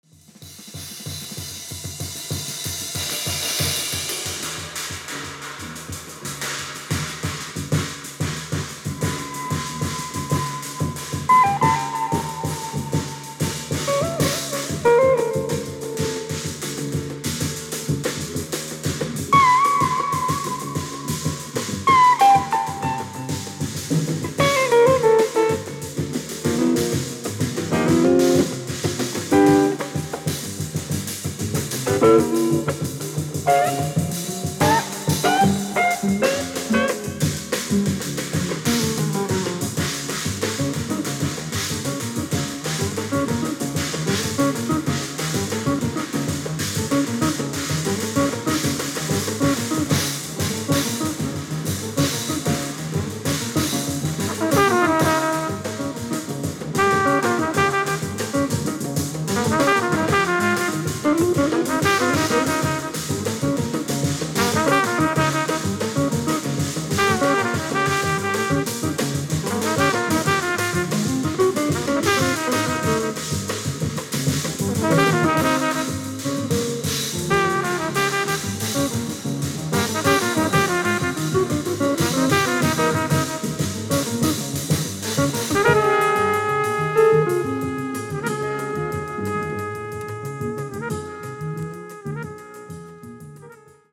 Bass
Drums
Guitar
Trumpet